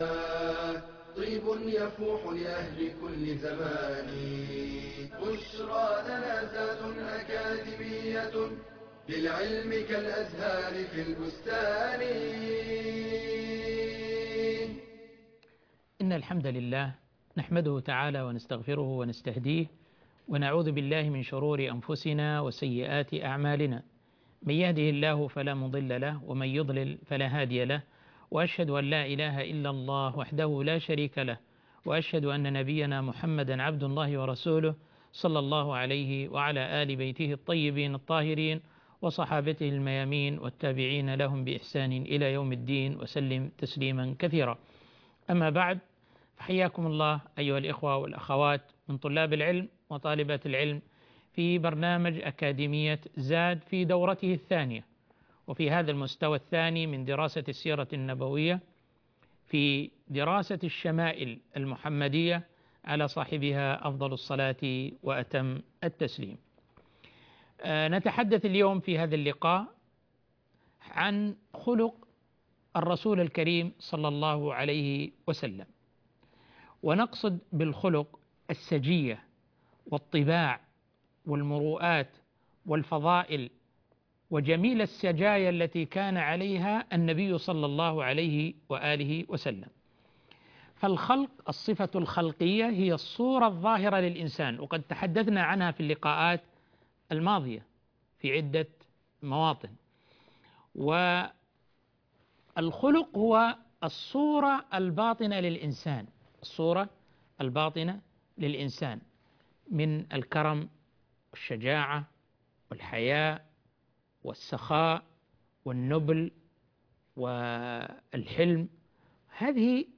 المحاضرة الثانية والعشرون- تحصيل الفضائل